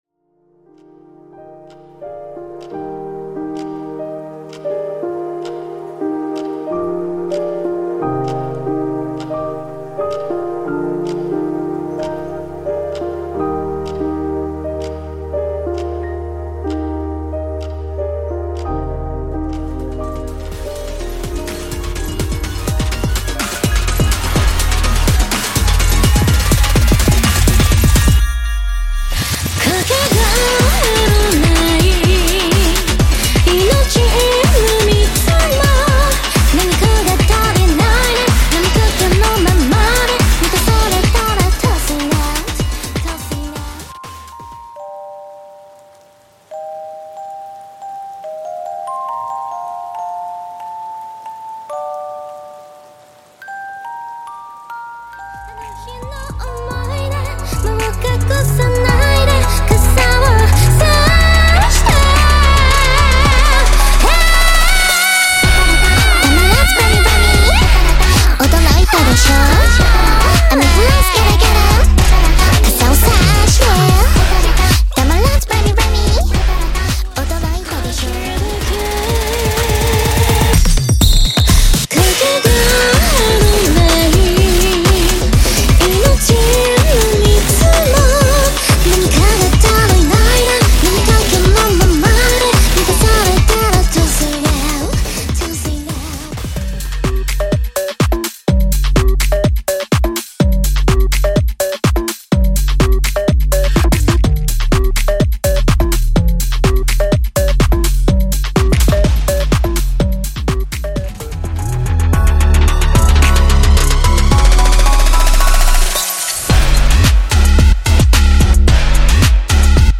ダンスポップ、EDMからアンビエントなインスト曲など含めた全７トラック